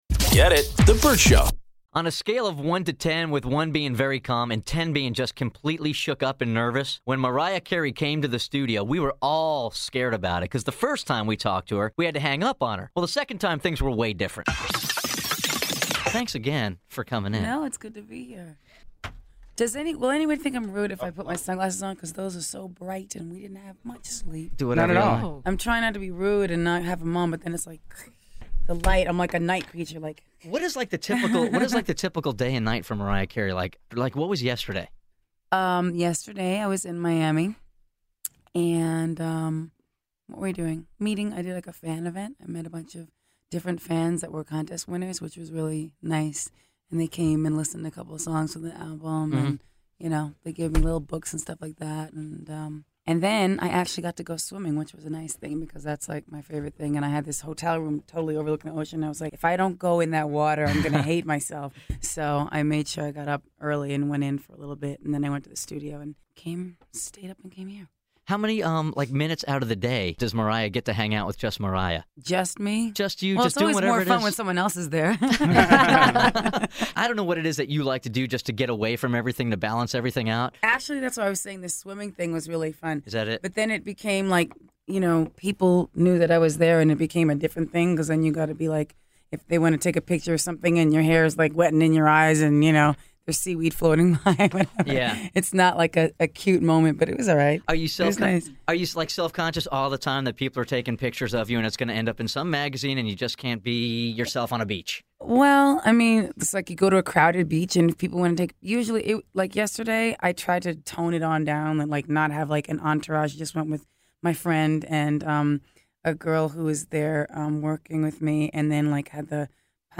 Vault: Interview - Mariah Carey